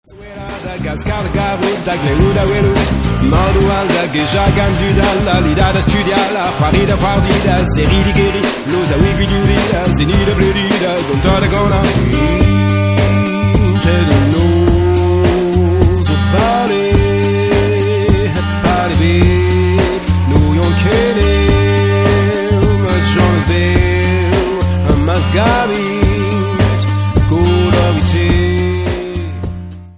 MP3 64kbps-Stereo